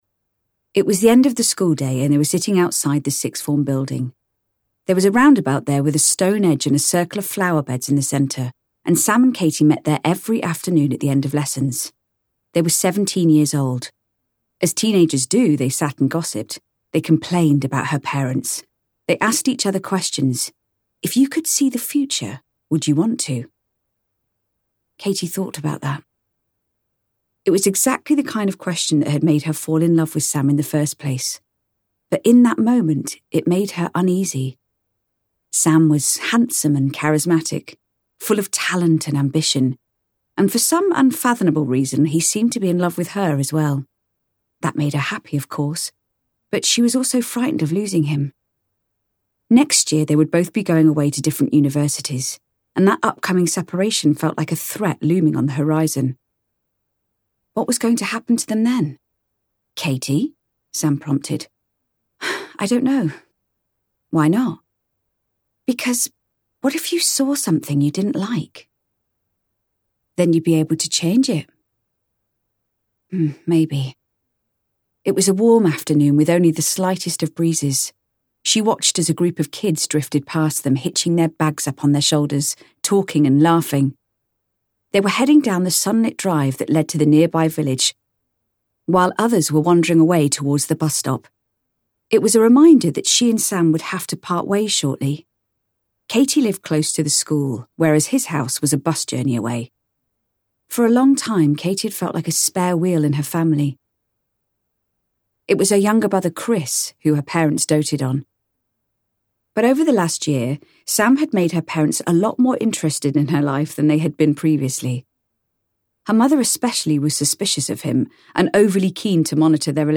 Listen to the first 10 minutes of the audiobook of The Angel Maker, narrated by London Evening Standard Award–winning actor Rosalie Craig, who played Virginia in Netflix’s 1899.